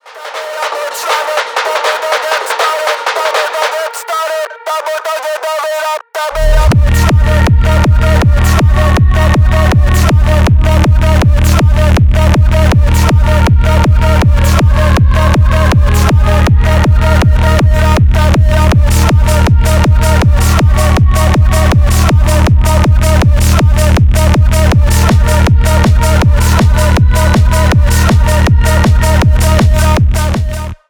bass boosted
рэйв